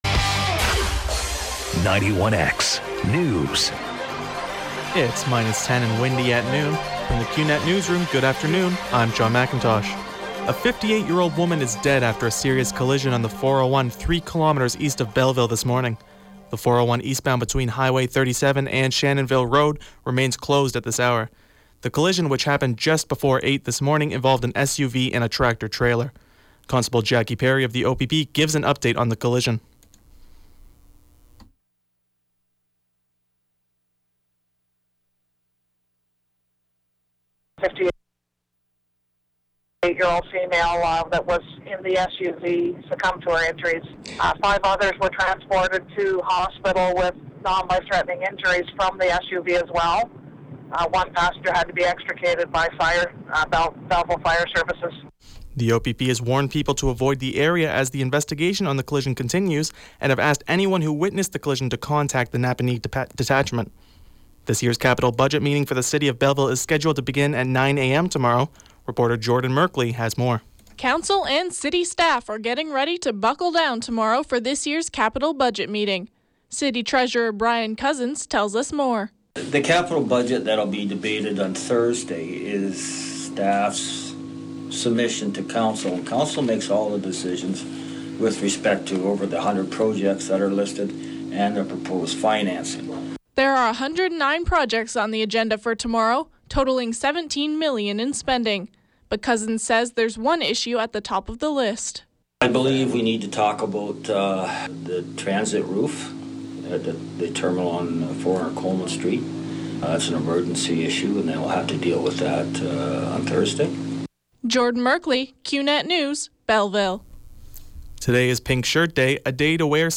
12 o’clock newscast